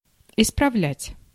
Ääntäminen
US : IPA : [pætʃ]